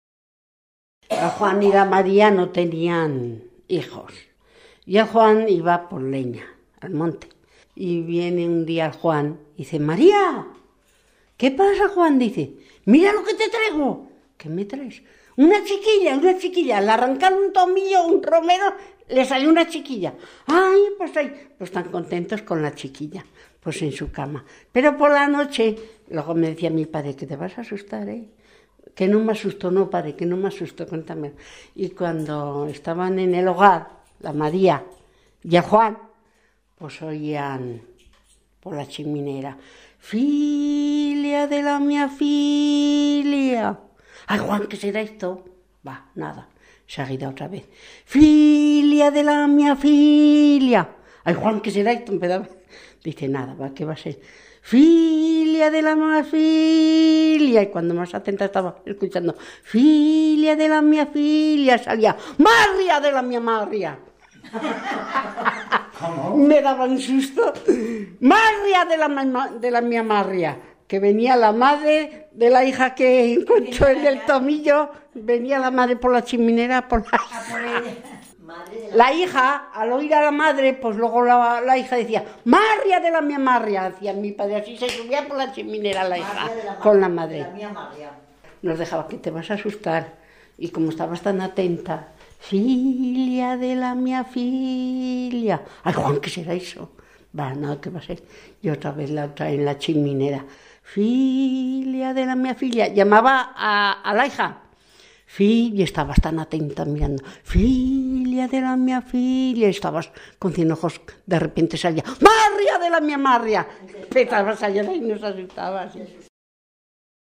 Clasificación: Cuentos